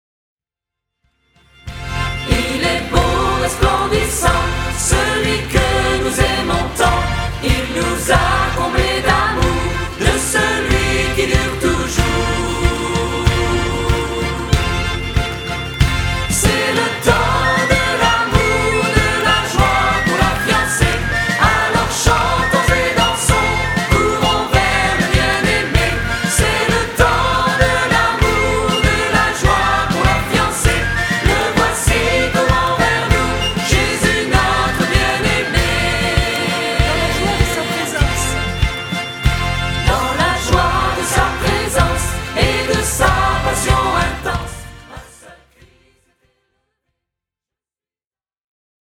Chants de louange originaux interprétés par leurs auteurs